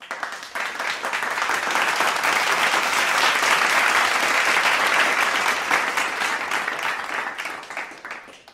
Sir Magnum Clapping - Bouton d'effet sonore